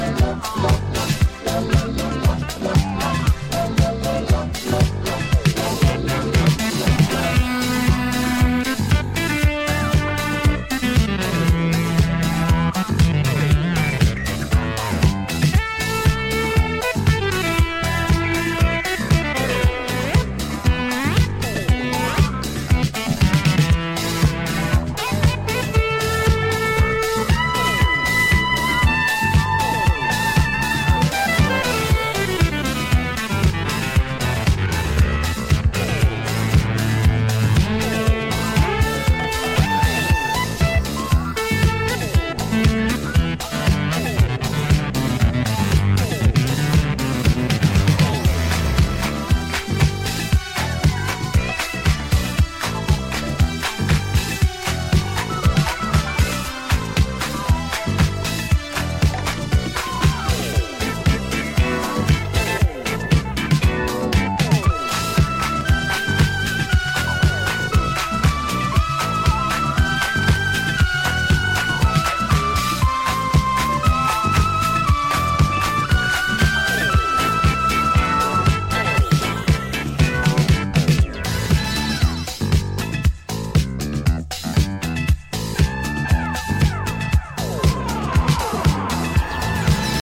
rare grooves